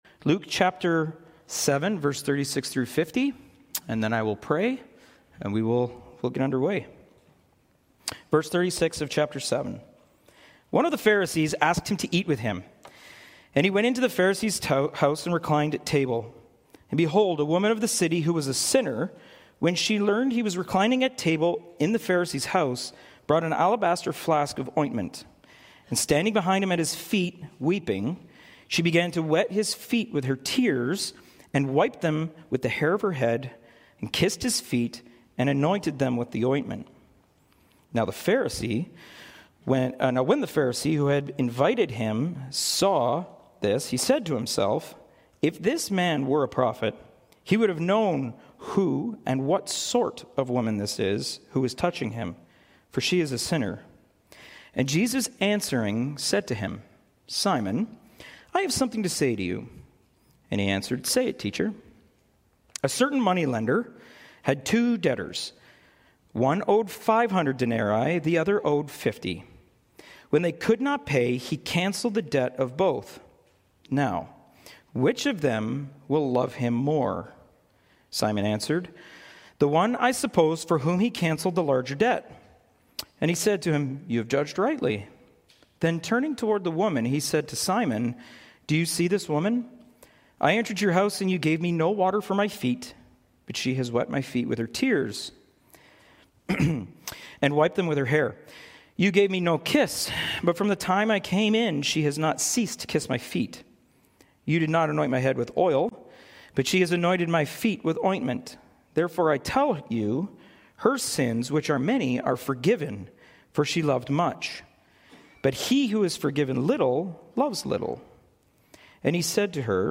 Preached by: Guest Speaker Passage: Luke 7:36-50